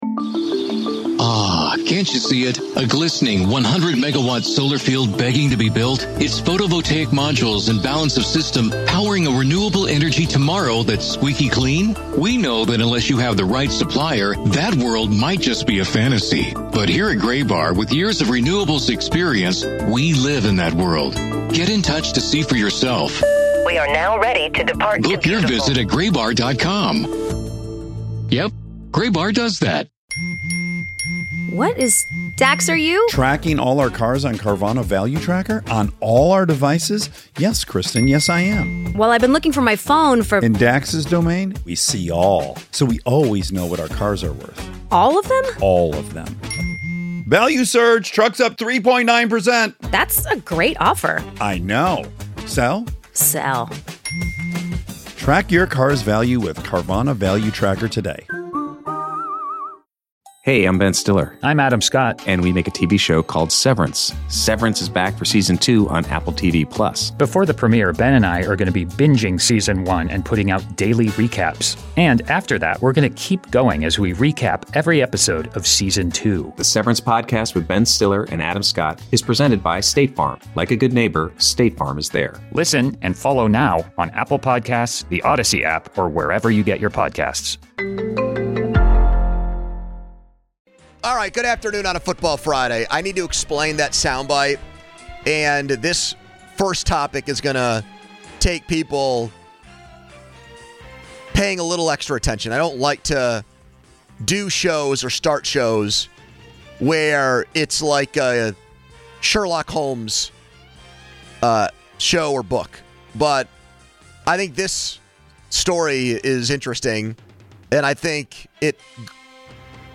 Callers chimed in on the future of Geo